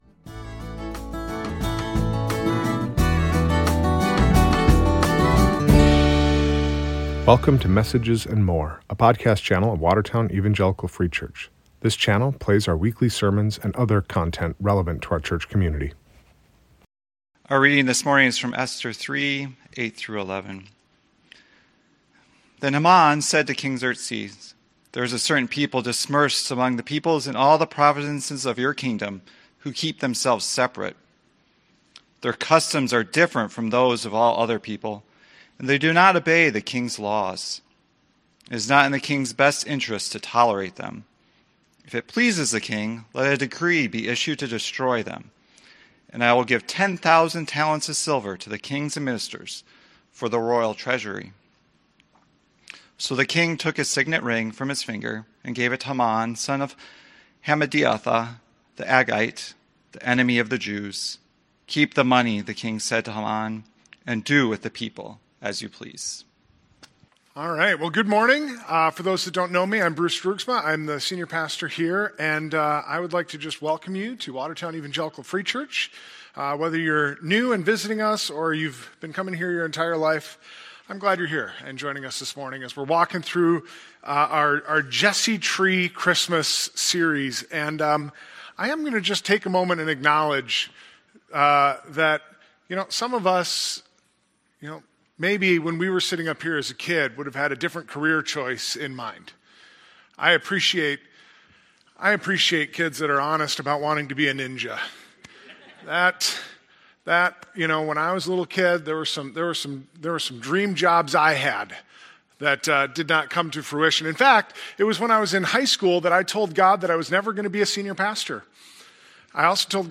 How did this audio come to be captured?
This is a channel where we post our Sunday morning and special holiday services as well as other content.